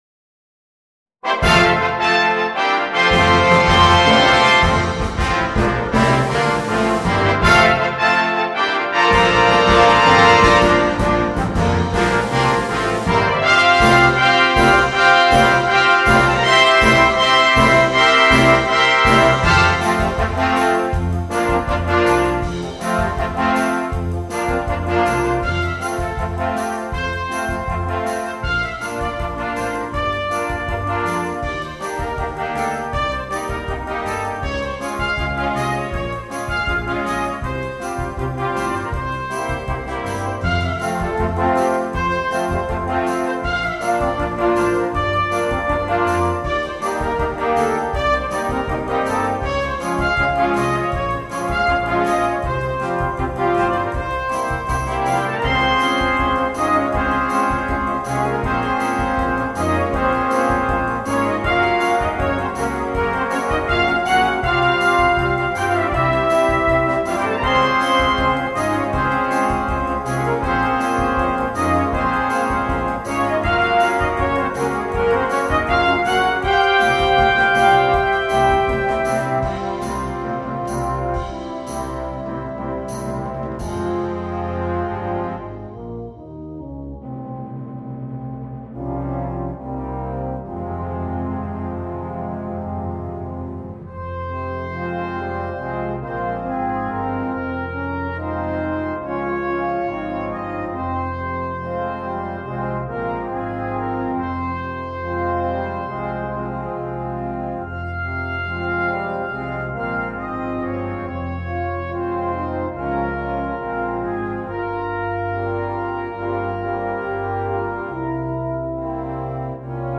2. Brass Band
komplette Besetzung
ohne Soloinstrument
Unterhaltung